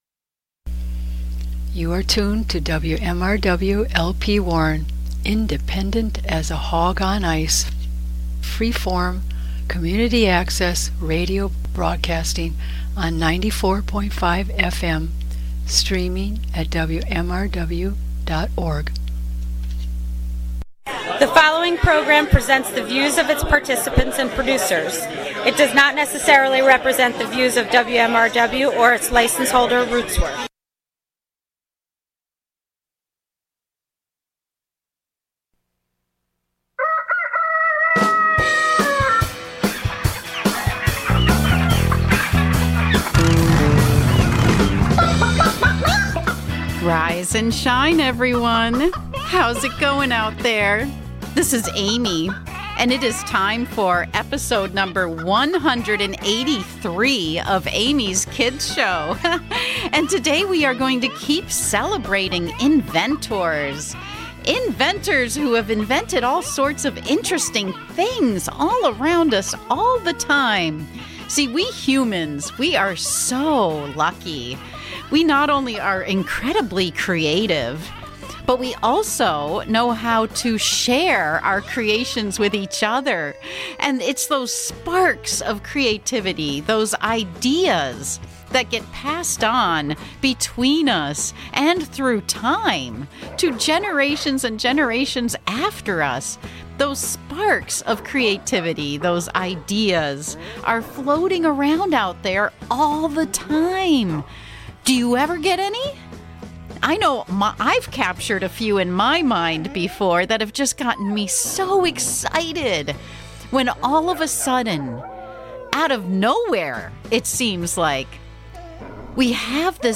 A Weekly Radio Show Celebrating Abundant Curiosity!